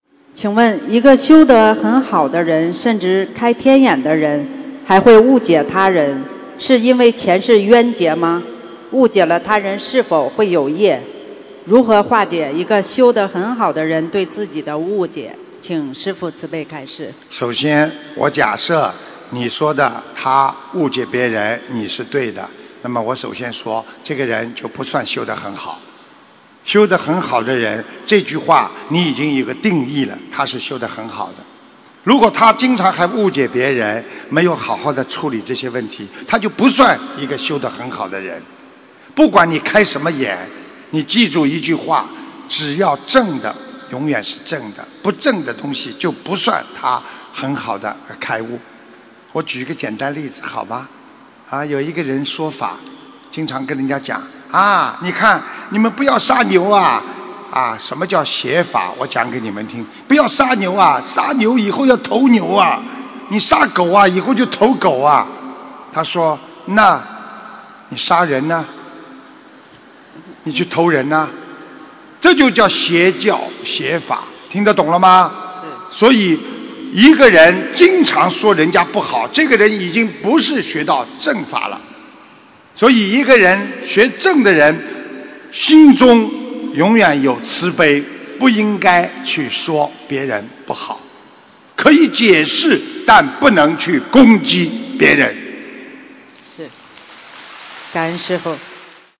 真正修得好的人心中永远有慈悲，不会攻击别人┃弟子提问 师父回答 - 2017 - 心如菩提 - Powered by Discuz!